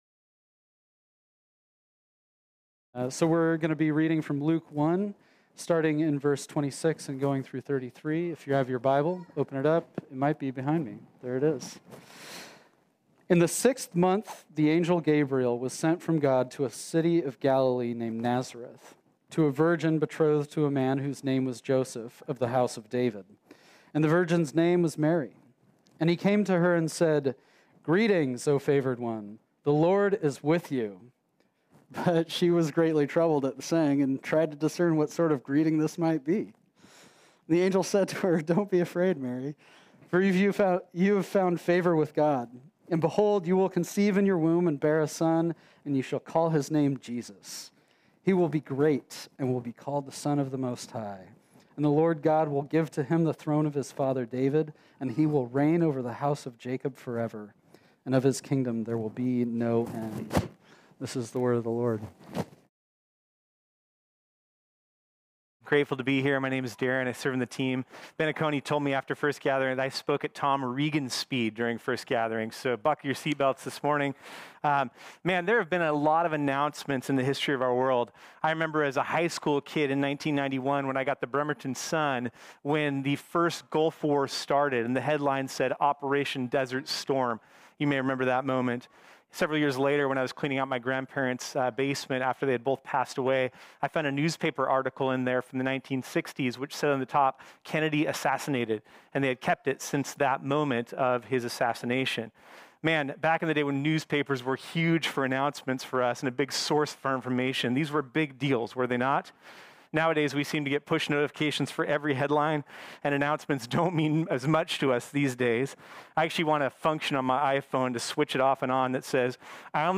This sermon was originally preached on Sunday, December 13, 2020.